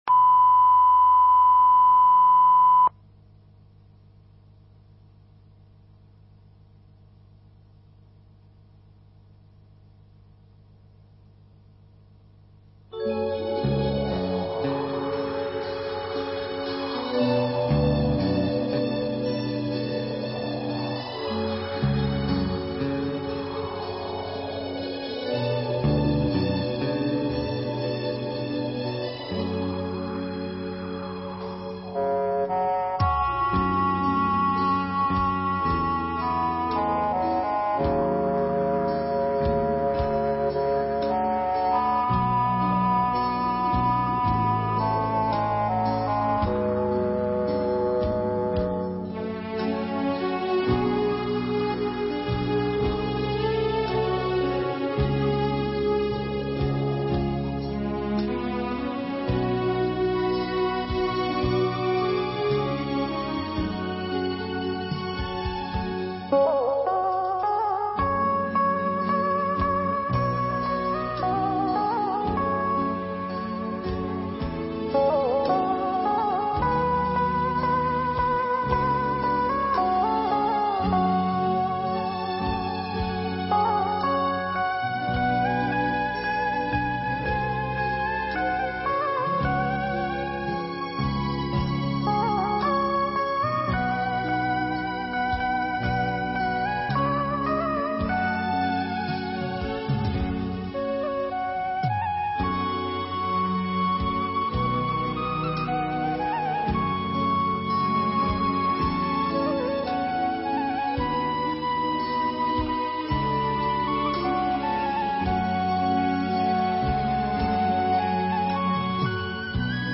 Mp3 Pháp Thoại Hai Ông Vua Xem Ngai Vàng Như Dép Rách